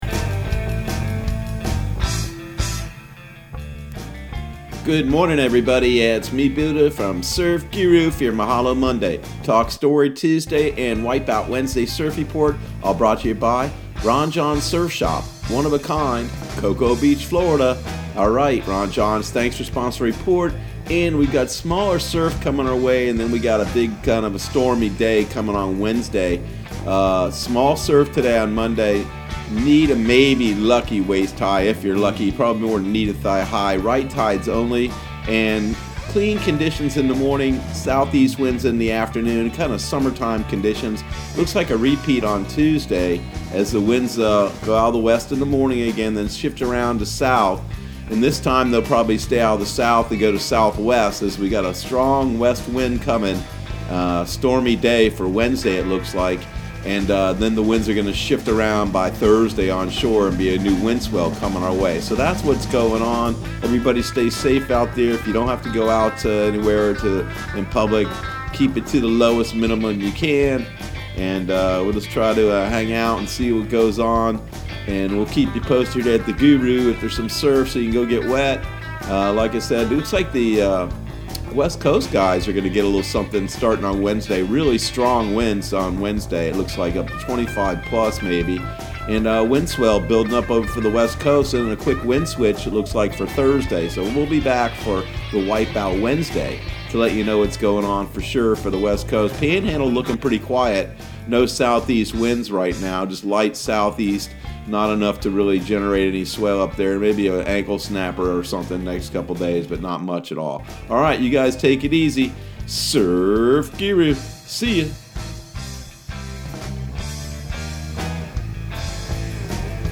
Surf Guru Surf Report and Forecast 03/30/2020 Audio surf report and surf forecast on March 30 for Central Florida and the Southeast.